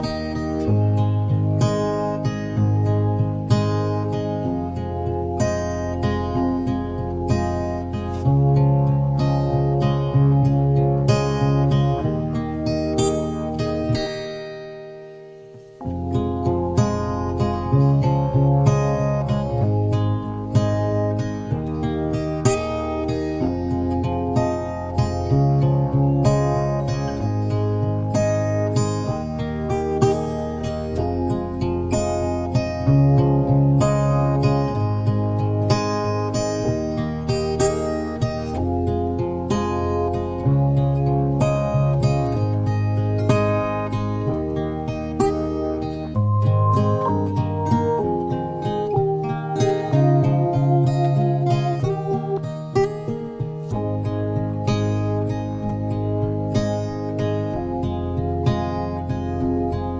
no Backing Vocals Duets 4:20 Buy £1.50